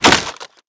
wood_bk_old.wav